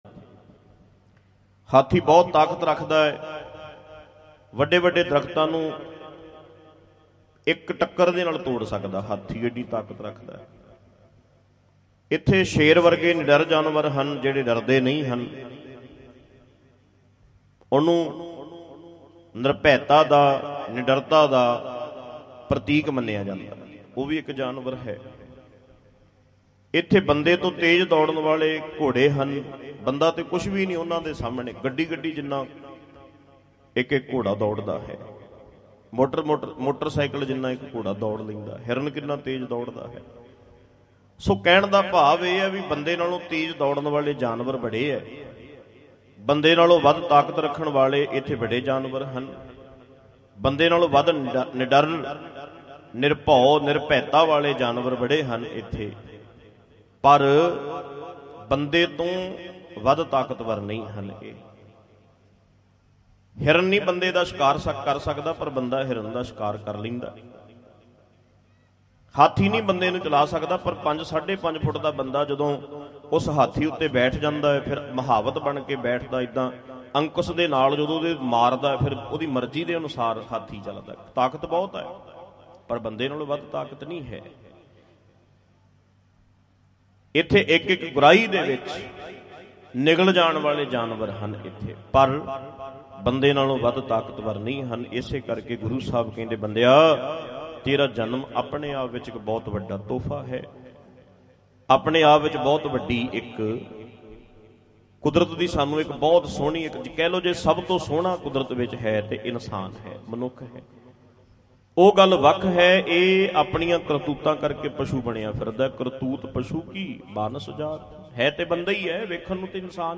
27 March 2022 Dhadrianwale Diwan at Guru | Dhadrianwale
Mp3 Diwan Audio by Bhai Ranjit Singh Ji Dhadrian wale at Parmeshar Dwar